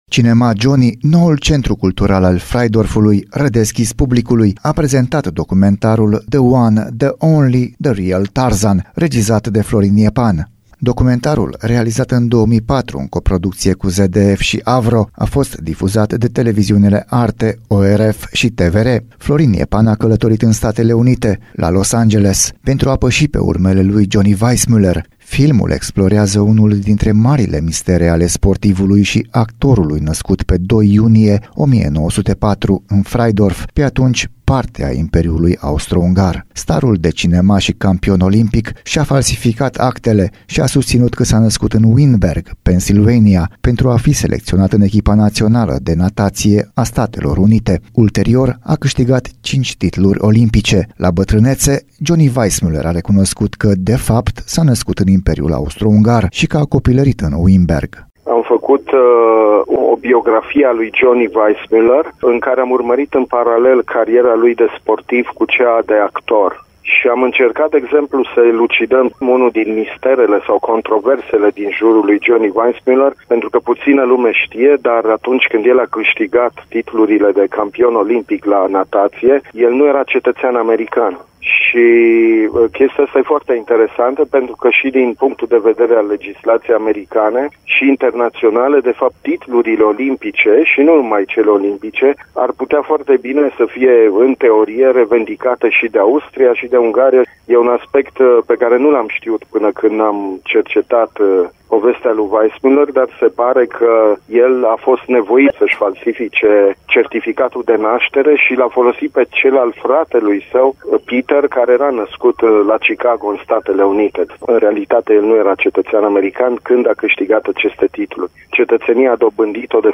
Reporter: